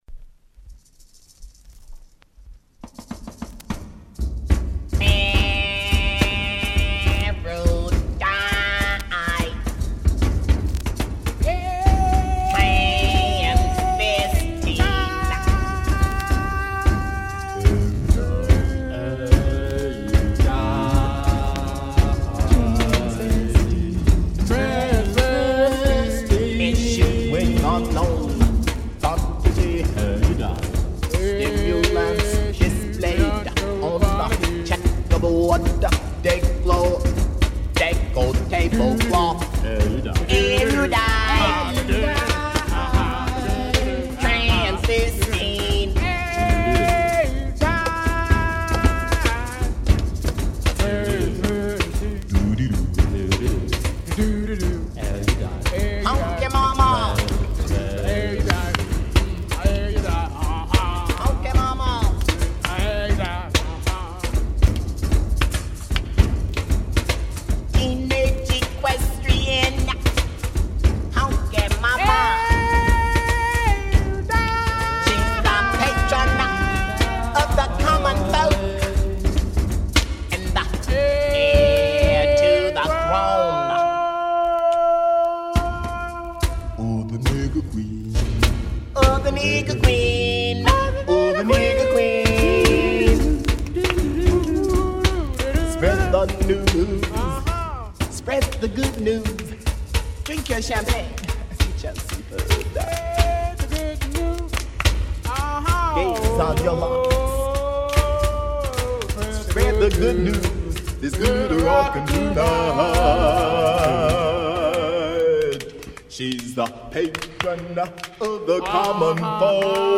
and in the timeless avant rock nature of the band.